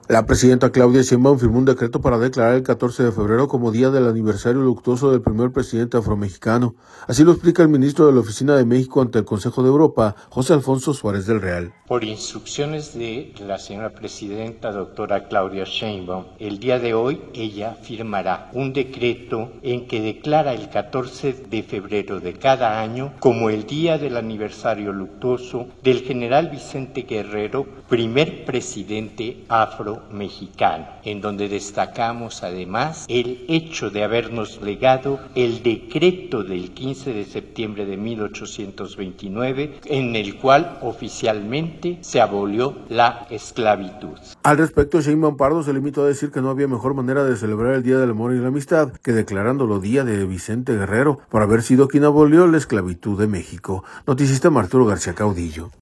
La presidenta Claudia Sheinbaum firmó un decreto para declarar el 14 de febrero como Día del Aniversario luctuoso del primer presidente afromexicano, así lo explica el ministro de la Oficina de México ante el Consejo de Europa, José Alfonso Suárez Del Real.